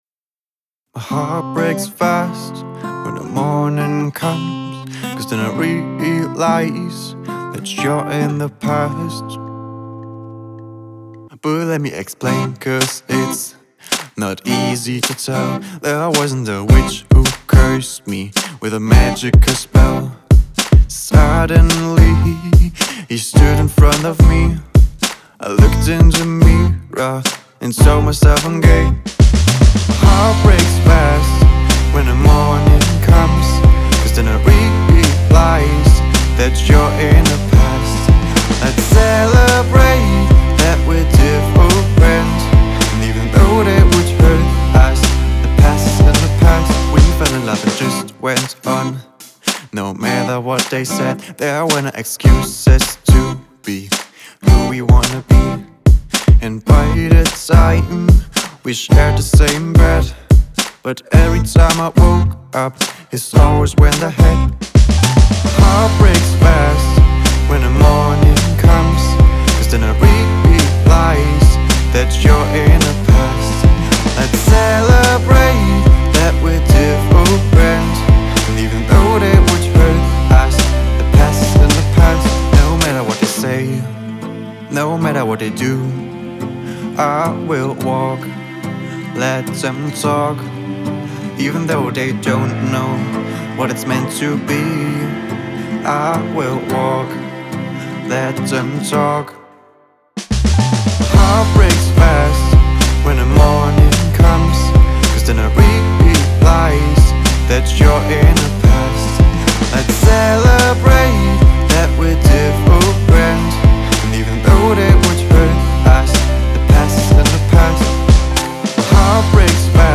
Autorenlesung (MP3)